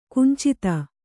♪ kuncita